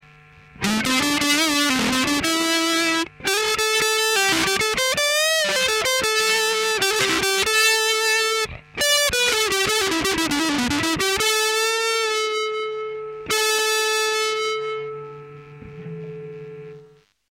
i recorded samples from my pedals to the point where its doin the "noise" and not necesserely to the max drive setting. the fender amp's EQ is set flat and recorded line coz couldnt with a mic(night time here). quality of the line is crap but still u can get the meaning. also i think it sounds lots more disturbing when hearing it directly from my amp. the speakers make it show more.
i used the neck(single coil) to record the samples.